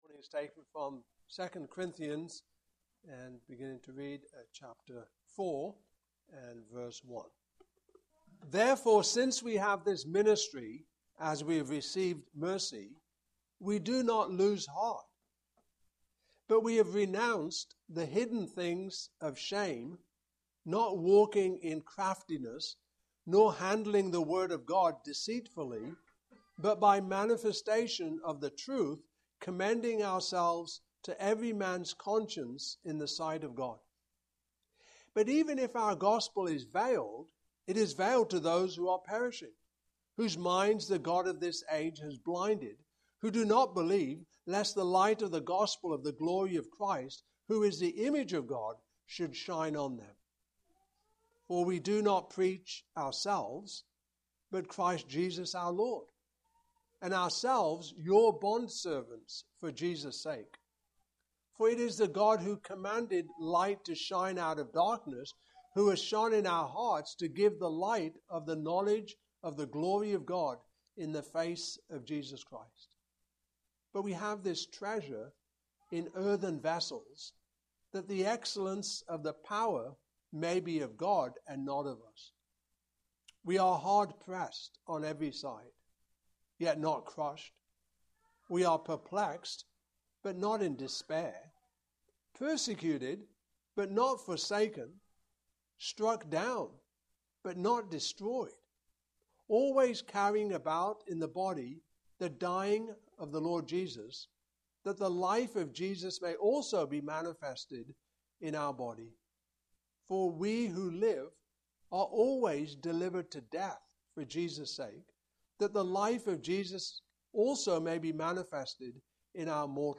Fruit of the Spirit Passage: 2 Corinthians 4:1-5:8 Service Type: Morning Service Topics